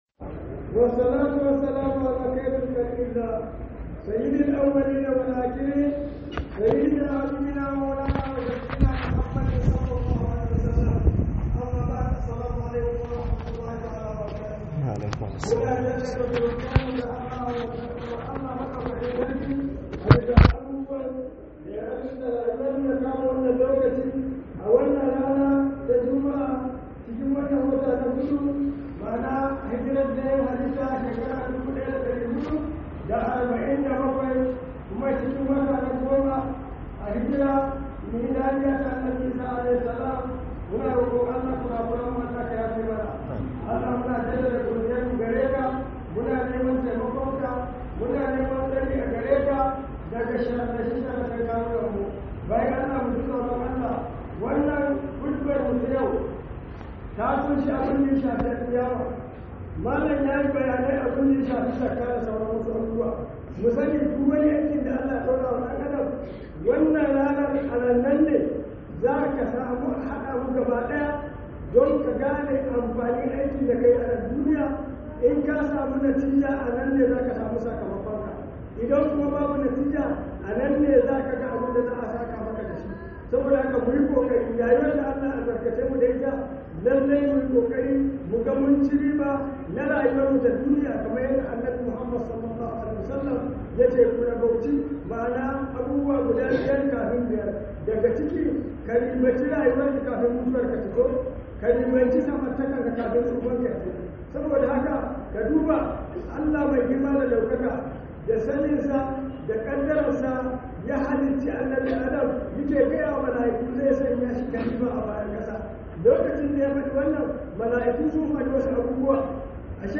Khudubar Sallar Juma'a by JIBWIS Ningi